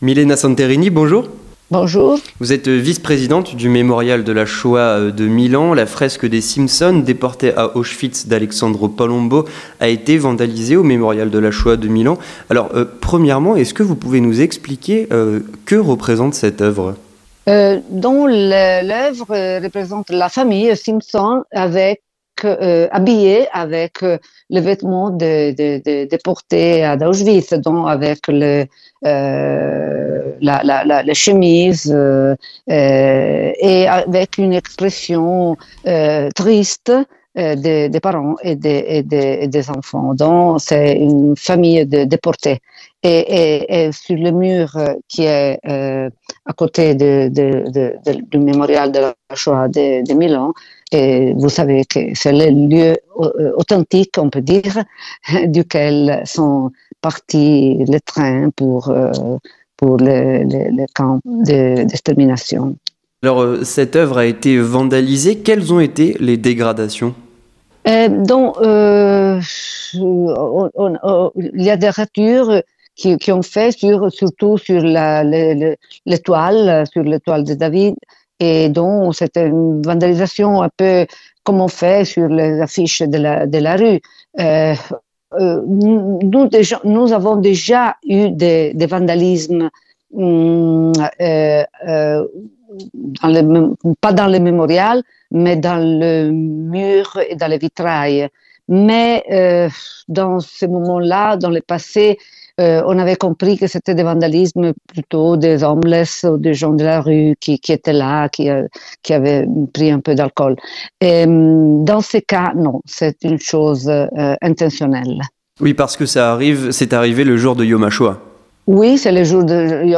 Entretien du 18h - Une fresque des "Simpsons déportés à Auschwitz" d'aleXsandro Palombo vandalisée
Avec Milena Santerini